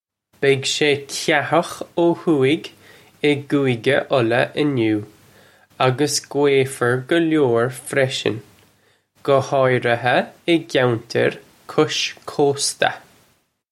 Pronunciation for how to say
Beg shay kahukh oh hoo-ig ih Goo-igga Ulla in-new uggus gway-fer galore freshin, guh hawriha ih gyantir kush koasta.